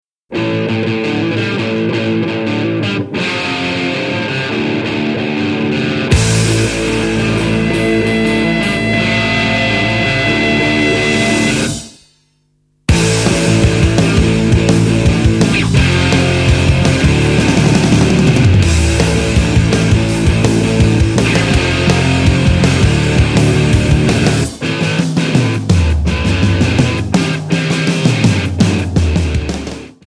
Alternative,Rock